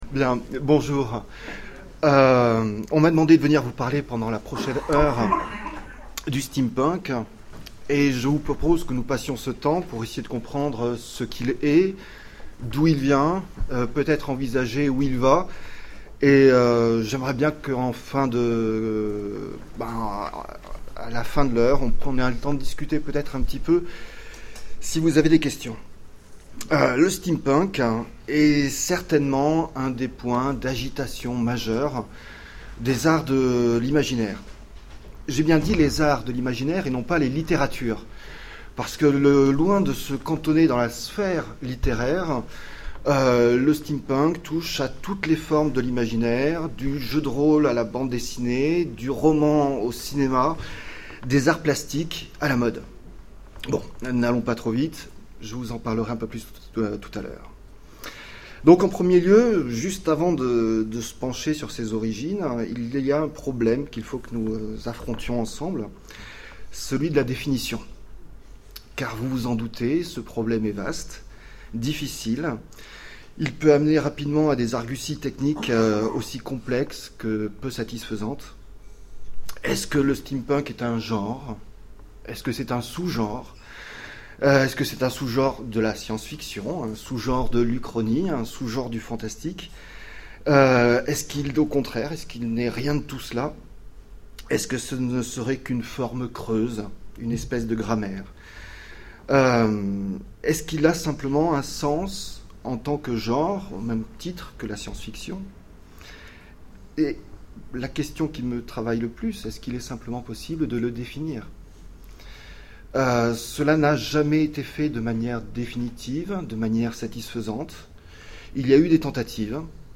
Rencontres de l'Imaginaire de Sèvres 2011 : Conférence sur le Steampunk